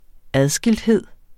Udtale [ ˈaðsgeldˌheðˀ ]